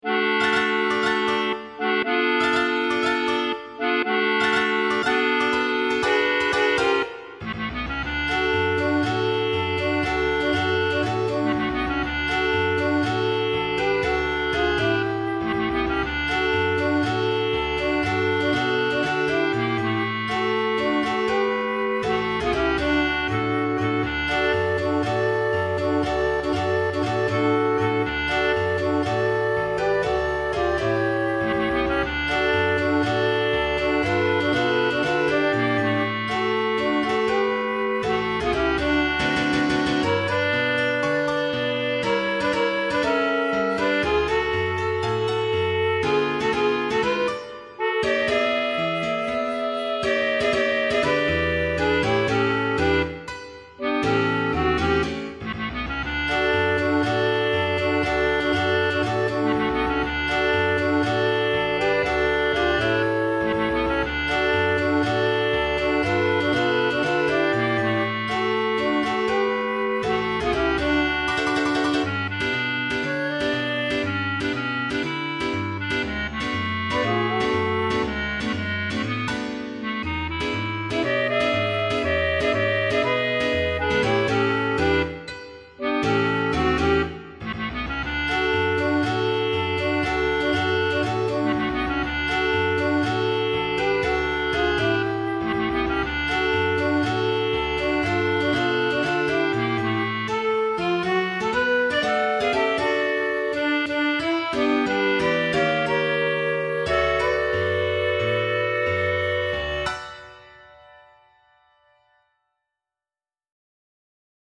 All Parts And Accompaniment